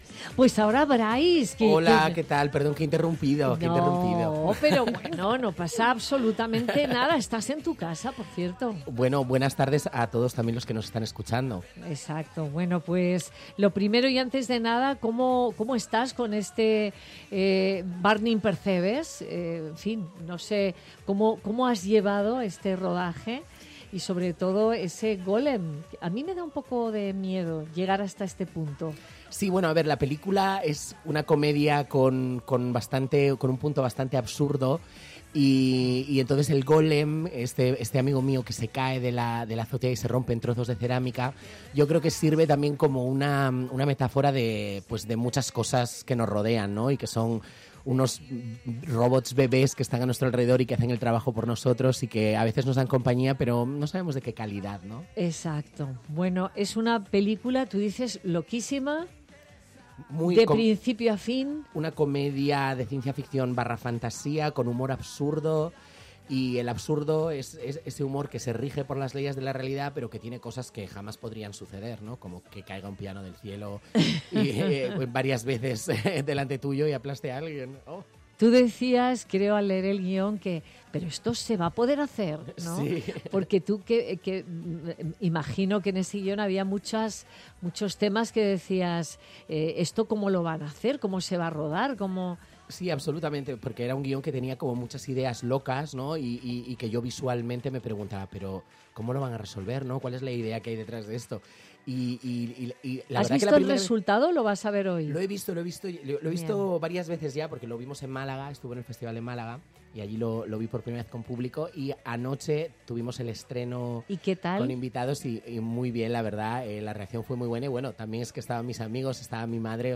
El actor Brays Efe ha pasado por los micrófonos de Madrid Directo con Nieves Herrero para hablar de su nueva película. Este viernes 16 de junio se estrena en cines El fantástico caso del Golem donde comparte reparto con Anna Castillo, Luis Tosar o Bruna Cusí.